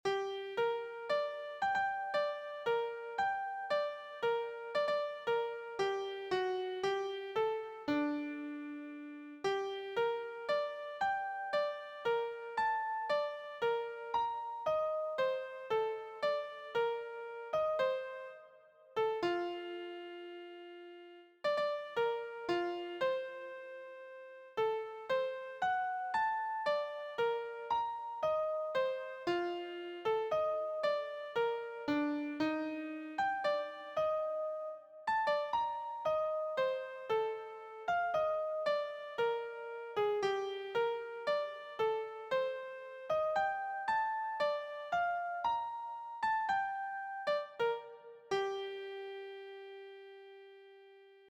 Instrumental nigun
Someday the first part of this might turn into a singing nigun -- but the range of the whole piece as composed here is too wide for most singers.
Nigun-composition.mp3